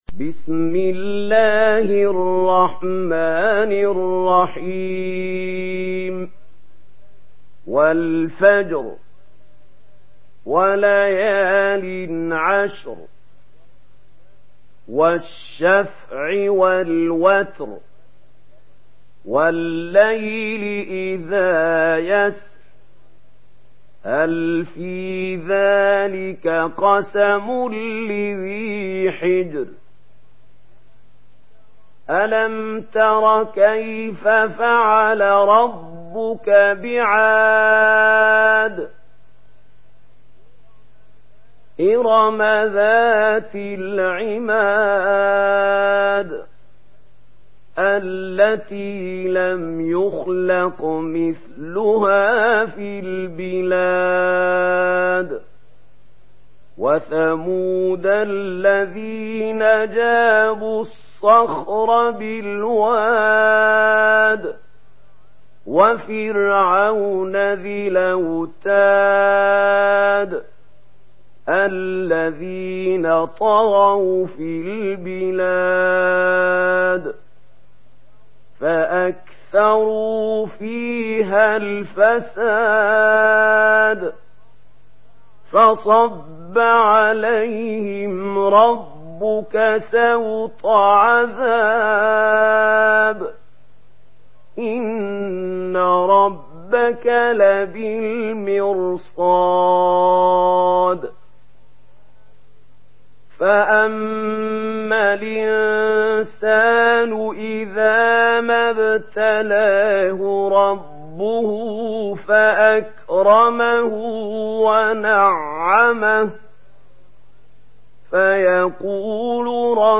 Surah আল-ফাজর MP3 in the Voice of Mahmoud Khalil Al-Hussary in Warsh Narration
Surah আল-ফাজর MP3 by Mahmoud Khalil Al-Hussary in Warsh An Nafi narration.
Murattal Warsh An Nafi